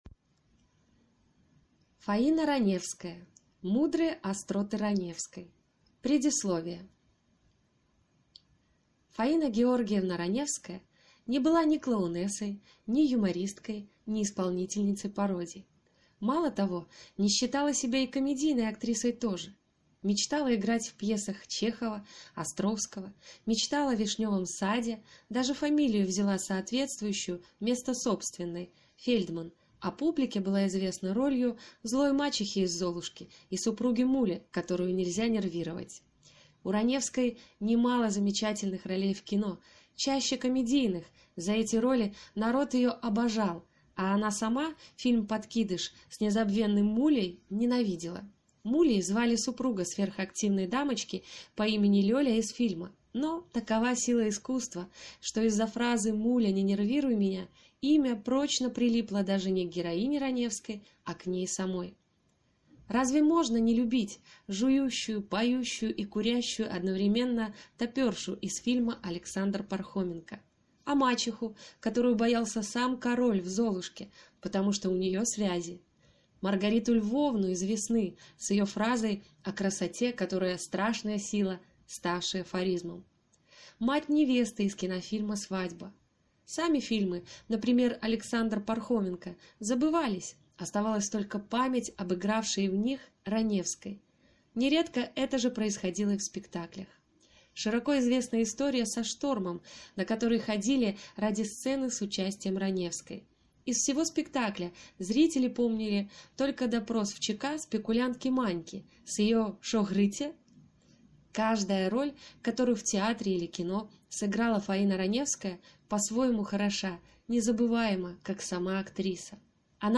Аудиокнига Мудрые остроты Раневской | Библиотека аудиокниг